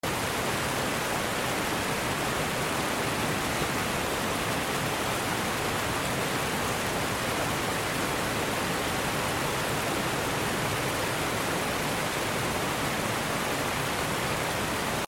The sound of the crashing water, the cool mist on my face, and the lush greens all around make this a truly unforgettable moment in nature 😍 Standing In Front Of This Sound Effects Free Download.